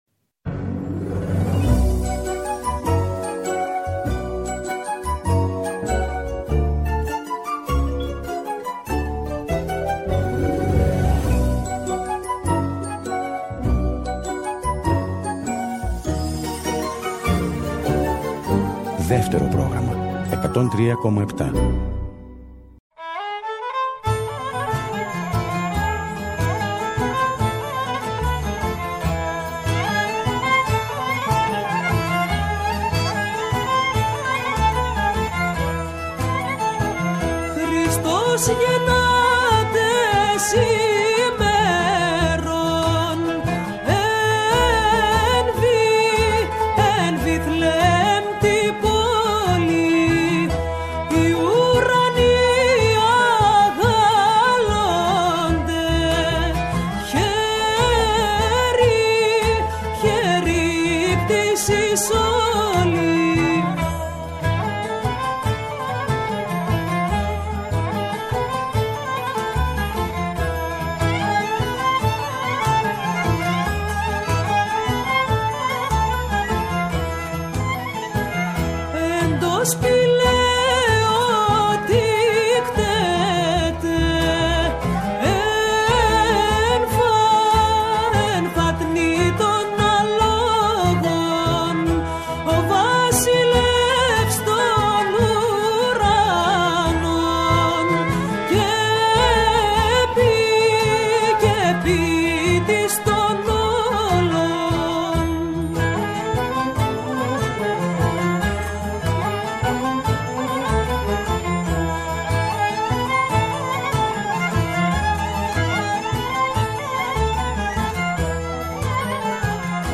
Παραμονή Χριστουγέννων και Σαββατόβραδο. Τι καλύτερο λοιπόν από μια εκπομπή με τραγούδια που αγαπήσαμε;